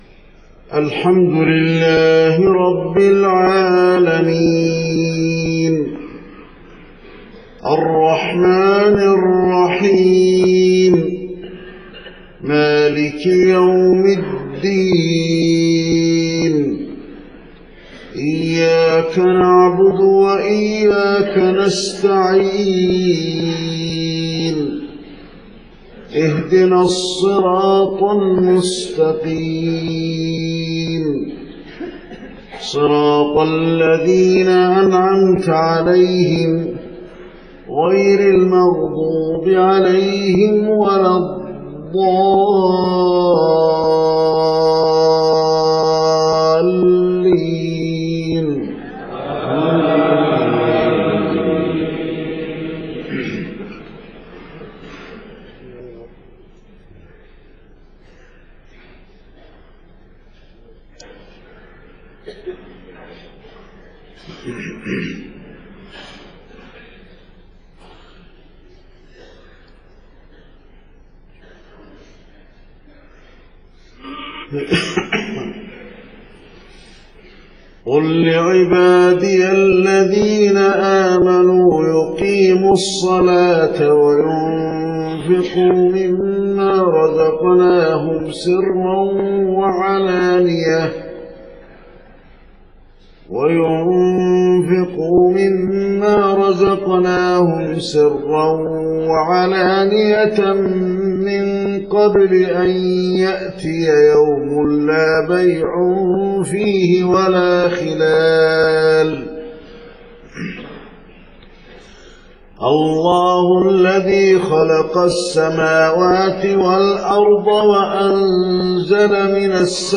صلاة الفجر 3 محرم 1430هـ خواتيم سورة إبراهيم 31-52 > 1430 🕌 > الفروض - تلاوات الحرمين